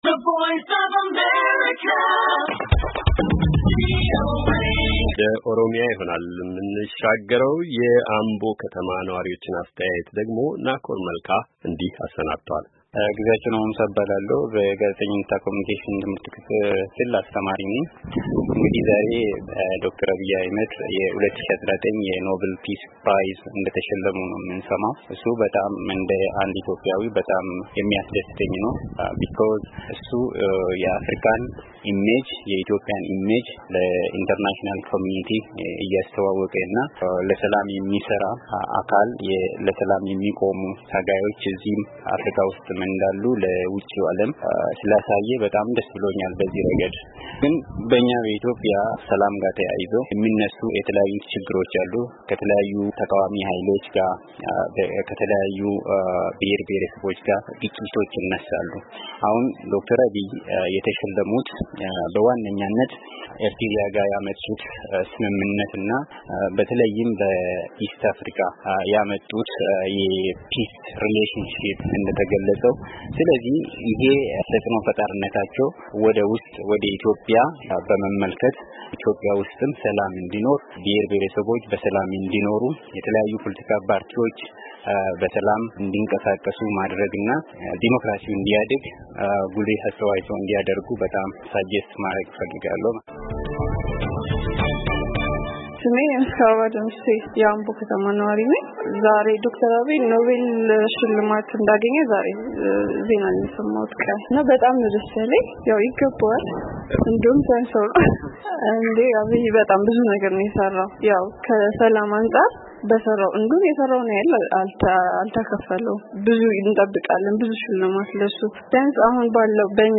የአምቦ ከተማ ነዋሪዎች በጠቅላይ ሚኒስትር አብይ አሕመድ የኖቤል የሰላም ሽልማትን በተመለከተ የሰጡት አስተያየት።